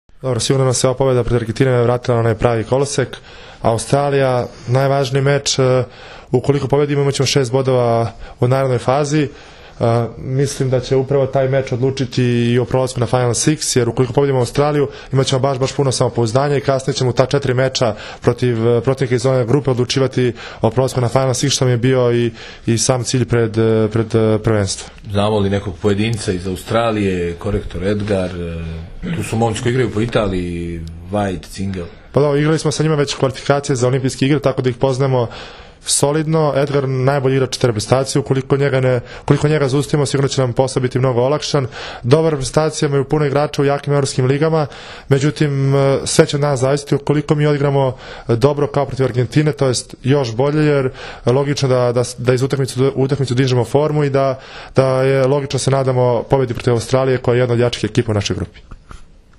IZJAVA ALEKSANDRA ATANASIJEVIĆA